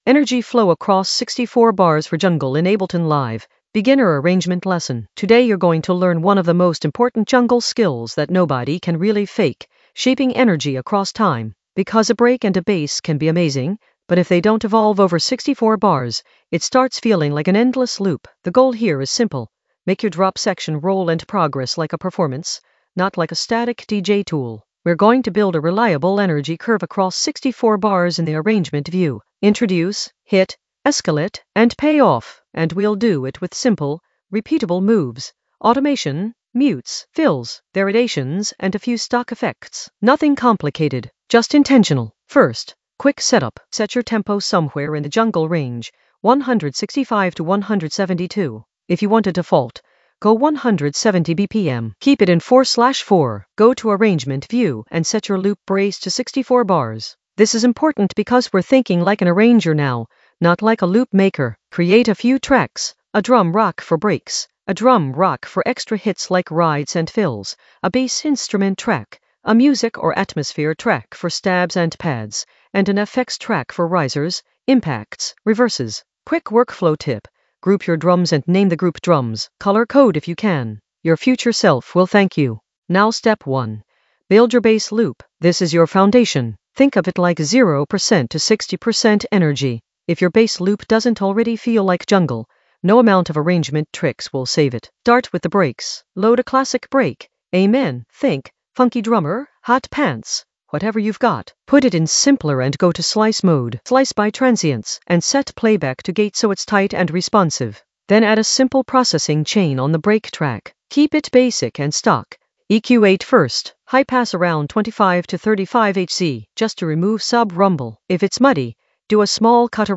An AI-generated beginner Ableton lesson focused on Energy flow across 64 bars for jungle in the Arrangement area of drum and bass production.
Narrated lesson audio
The voice track includes the tutorial plus extra teacher commentary.